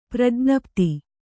♪ prajñapti